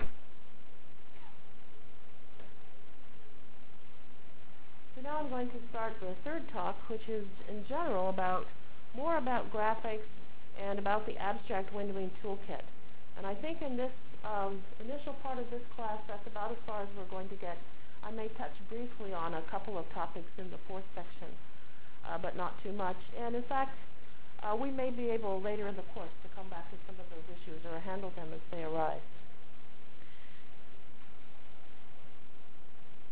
From Jan 29 Delivered Lecture for Course CPS616